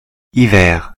Ääntäminen
ÄäntäminenFrance (Paris):
• IPA: [i.vɛːʁ̥]